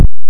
简单的电子脚
描述：简单的电子踢/低音鼓标准化为0.1dBCreative Commons 0
标签： 电子 kick-鼓 kickdrum bassdrum 电子鼓 bassdrum
声道立体声